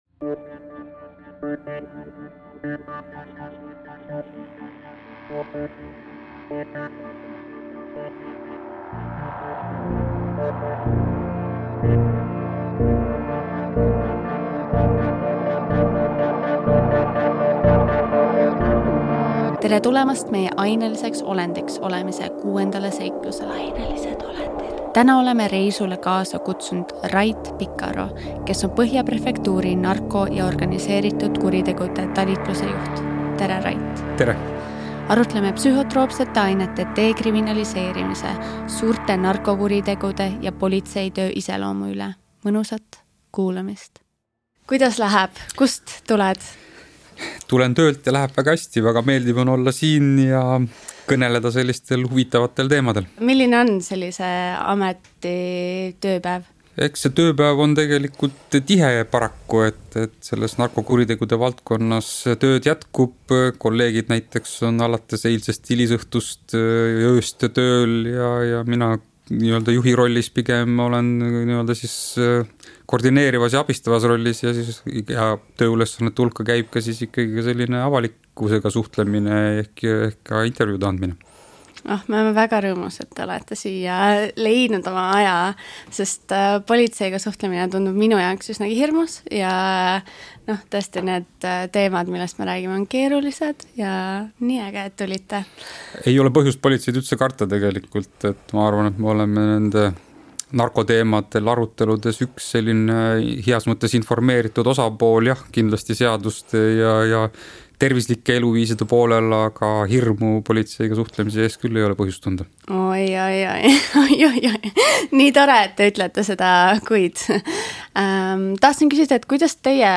Kas võimud teevad diile kuulsustega, kes on narkoga vahele jäänud?Sarja "Ainelised olendid" eesmärk on muuta psühhotroopsete ainete ümber käivaid vestlusi sisulisemaks – mitte hirmutada, vaid rääkida ainete headest ja halbadest külgedest. Igas saates on koos saatejuhiga üks külaline, kellega tulevad jutuks nii teadmised, müüdid kui ka inimeste isiklikud kogemus